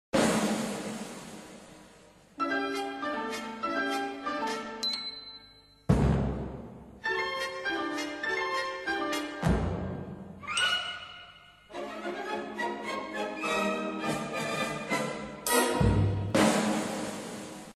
Orchestersuite | Ballettparodie